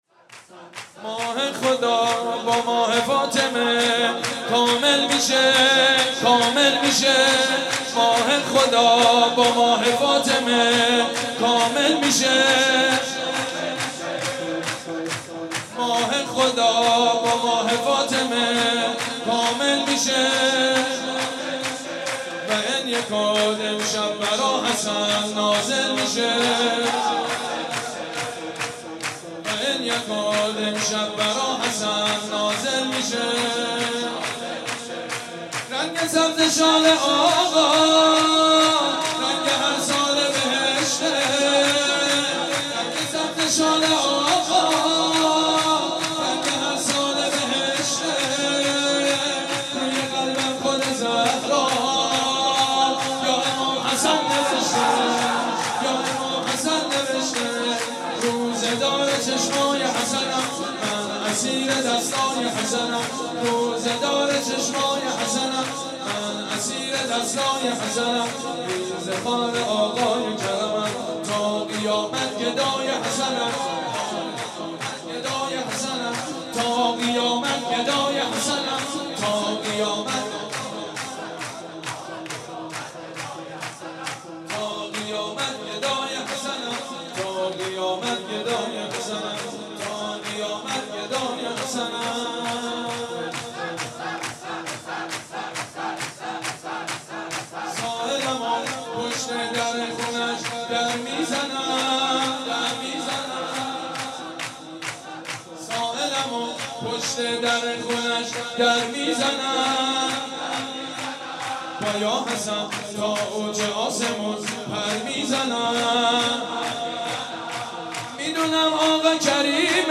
حاج سید مجید بنی فاطمه/شام میلاد امام حسن(ع) رمضان93